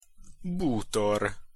Pronunciation Hu Bútor (audio/mpeg)